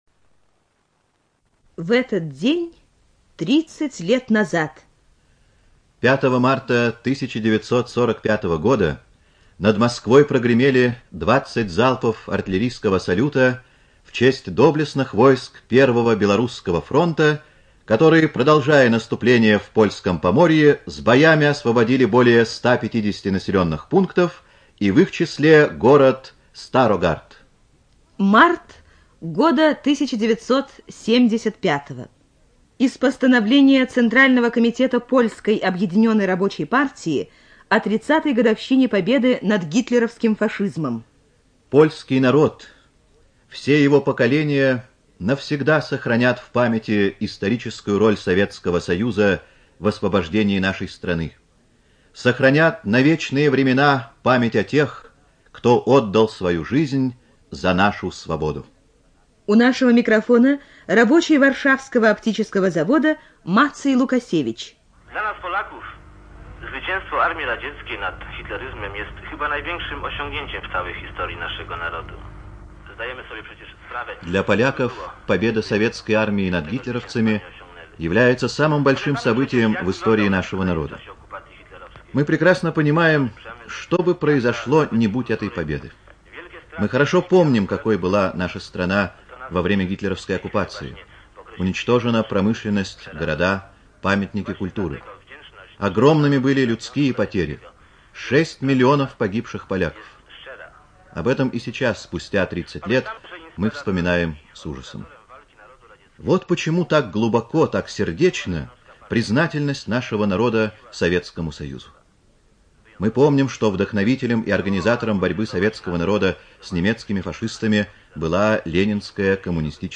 ЖанрДокументальные фонограммы
Студия звукозаписиРадио Маяк